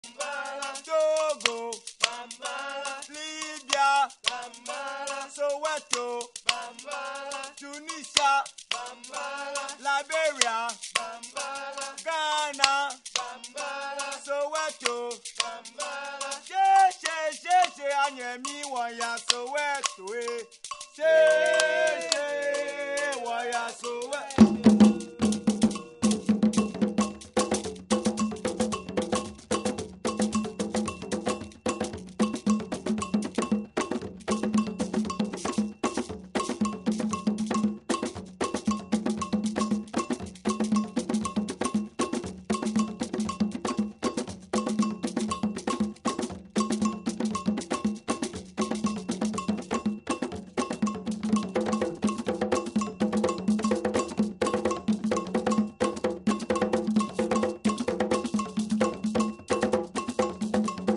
Performed/recorded in Ghana, West Africa.
Music represented includes Atsia (Ewe), Kpanlogo (Ga) and Kinka (Ewe) among many other styles.
kpanlogo.mp3